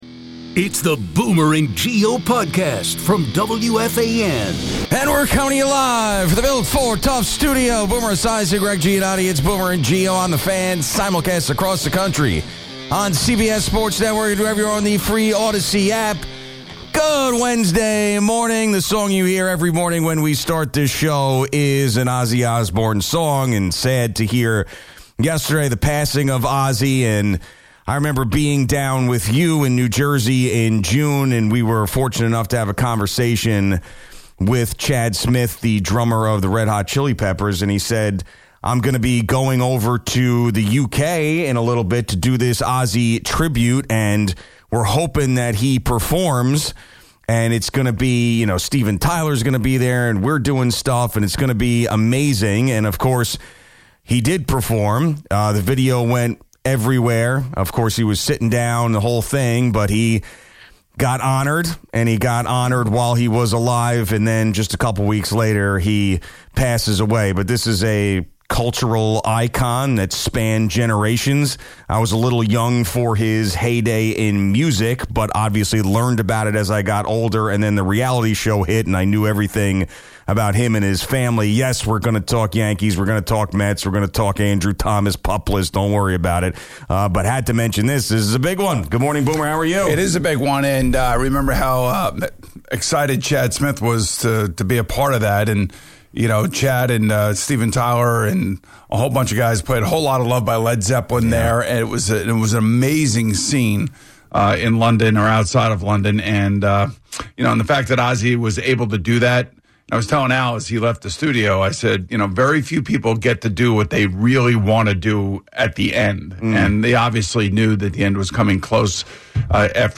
It's boomer and Gio on the fan simulcasts across the country.